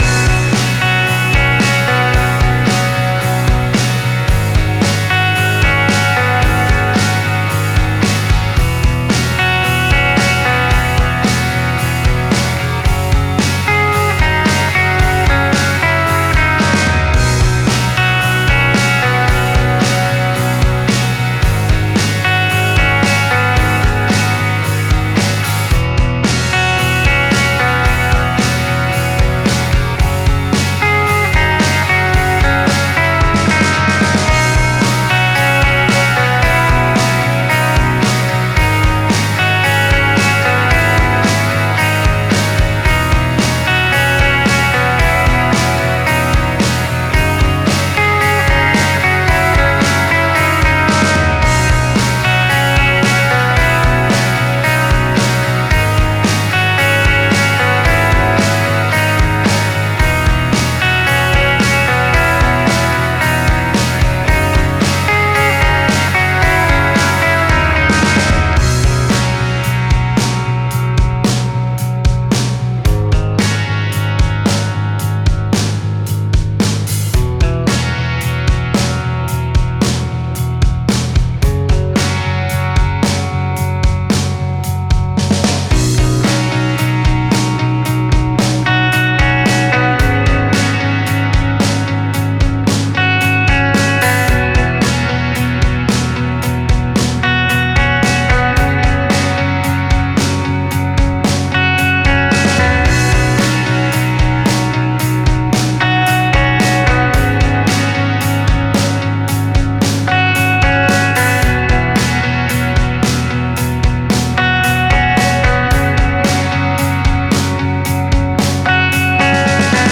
ゆったりと和む幻想的なロック